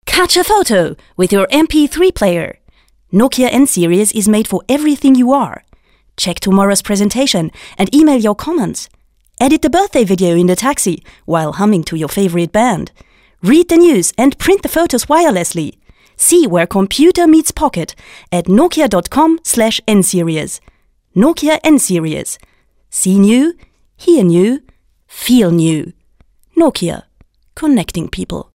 Als Schauspielerin habe ich natürlich großen Spaß daran mit meiner Stimme zu spielen, daher freue ich mich auch, wenn ich als "Sprecherin" für Werbung, Dokumentation, Hörspiel, Film- und Zeichentrickfilmsynchronisation und Voice-Over zum Einsatz komme.
mp3  Werbetext - "Nokia", Englisch  [445 kB]